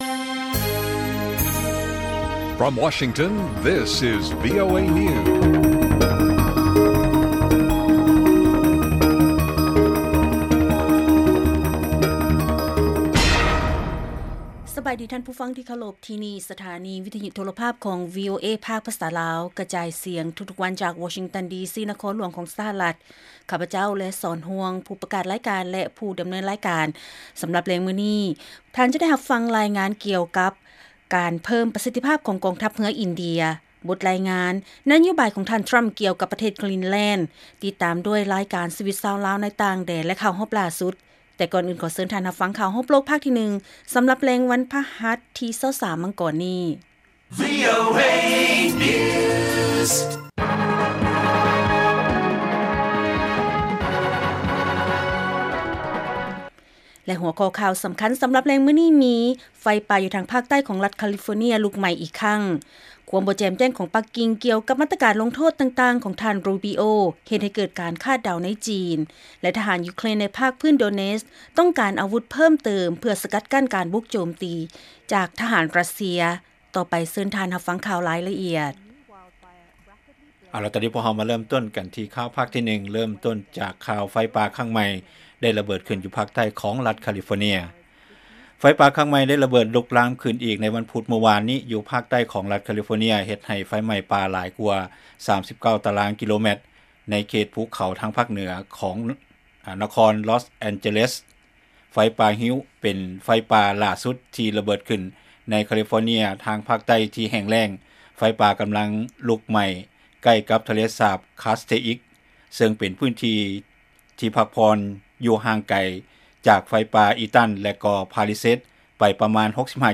ລາຍການກະຈາຍສຽງຂອງວີໂອເອລາວ: ໄຟປ່າຢູ່ທາງພາກໃຕ້ຂອງລັດຄາລິຟໍເນຍ ລຸກໄໝ້ອີກຄັ້ງ